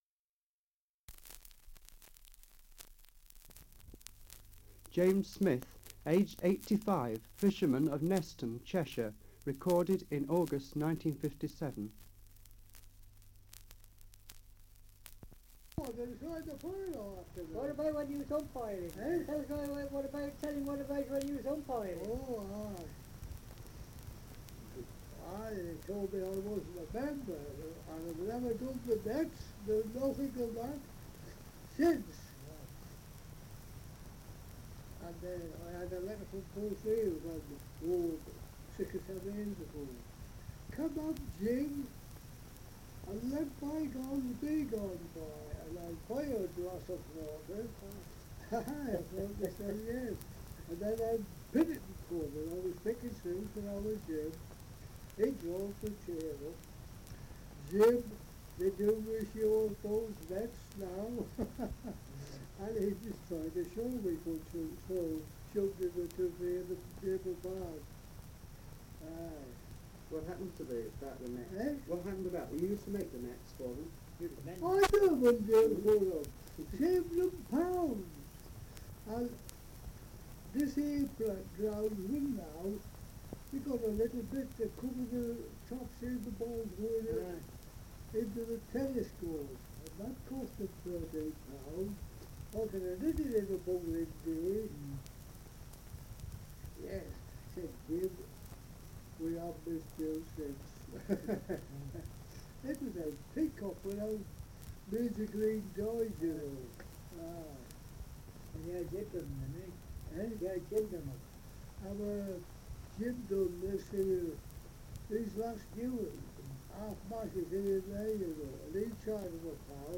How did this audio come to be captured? Dialect recording in Neston, Cheshire 78 r.p.m., cellulose nitrate on aluminium